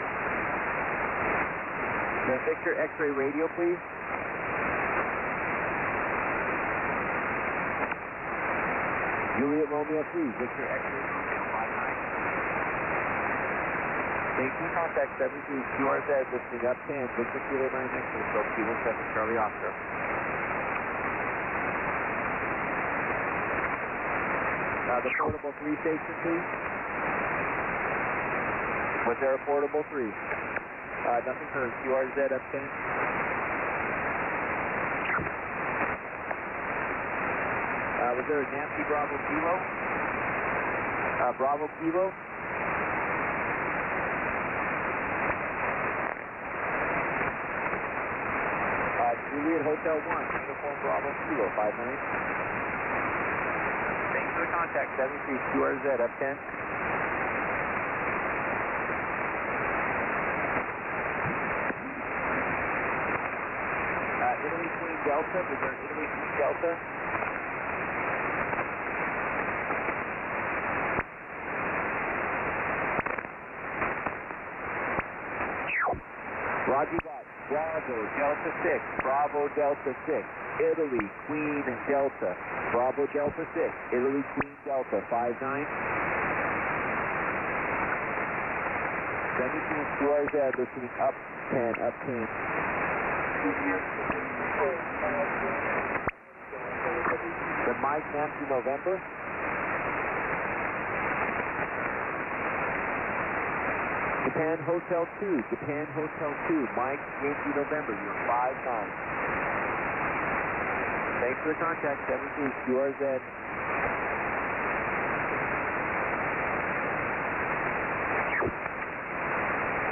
17M SSB SP